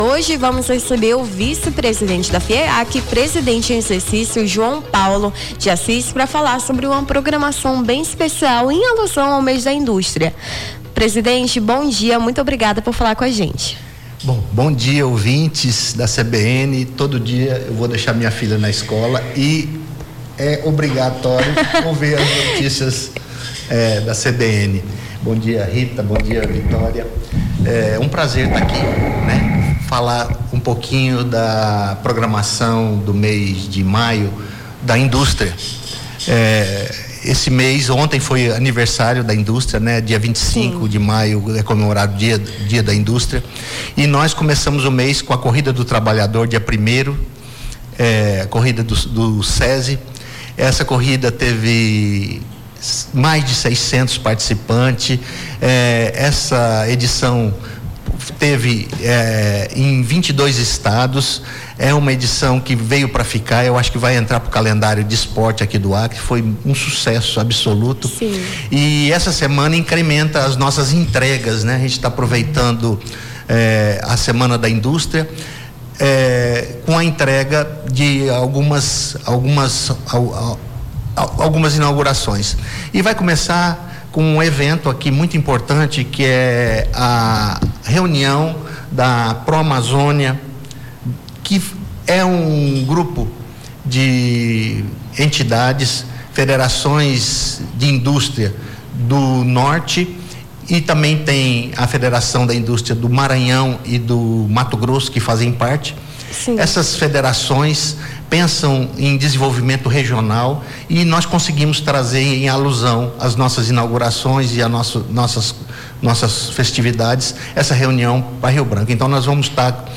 Nome do Artista - CENSURA - ENTREVISTA MÊS DA INDÚSTRIA - FIEAC (26-05-25).mp3